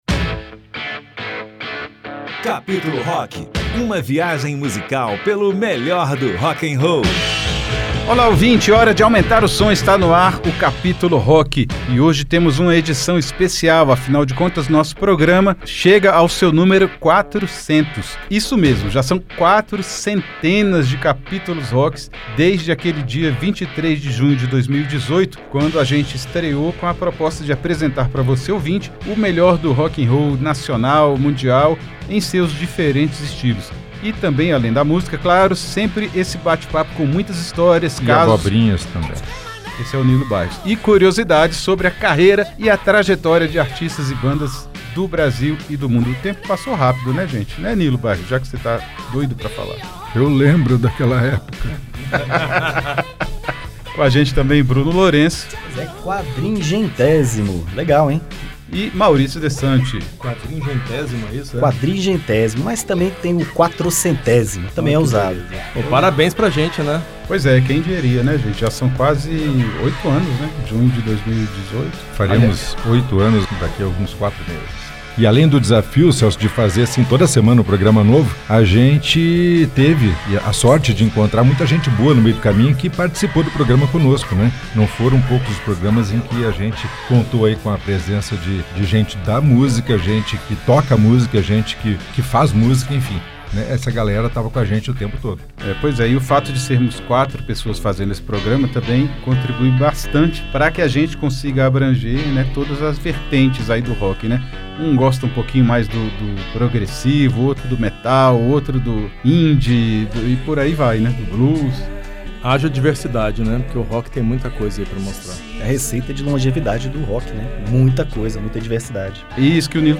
A cada Capítulo, uma história e uma playlist recheada de clássicos, lados “B” e lançamentos. Tudo regado a um bate-papo descontraído com casos e curiosidades do mundo do rock.